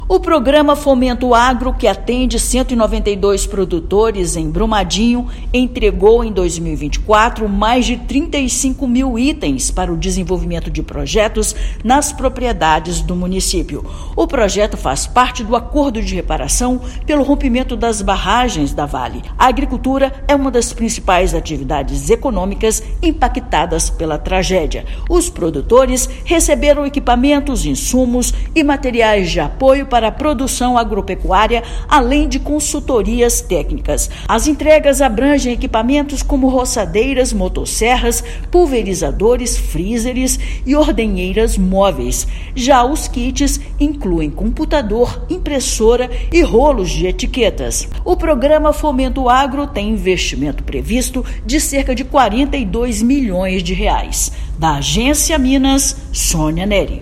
Iniciativa do Acordo de Reparação atende 192 produtores, incrementa atividade agrícola e fortalece a economia da região. Ouça matéria de rádio.